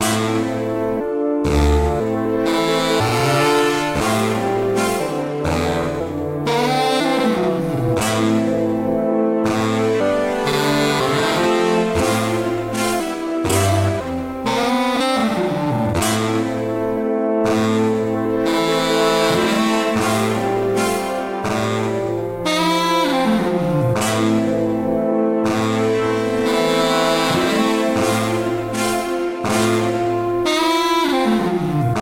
サックスのみで構成されたBGM。